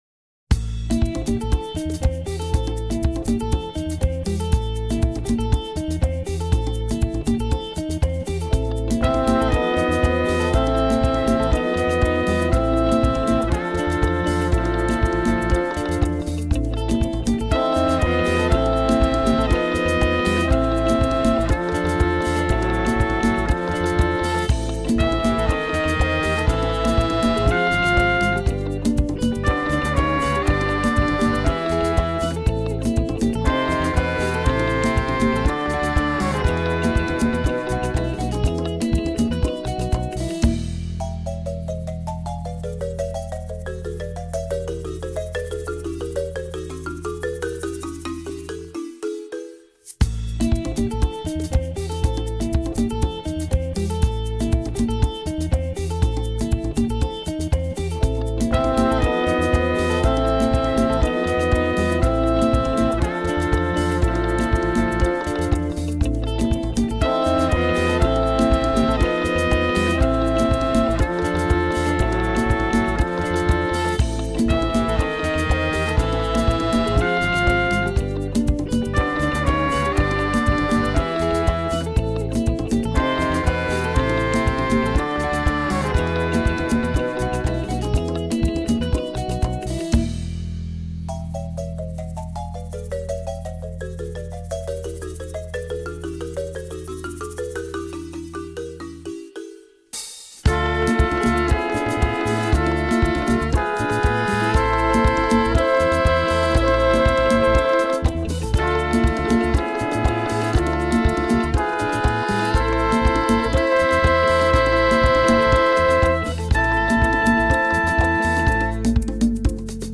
Un conte musical
Une histoire et 12 musiques modernes africaines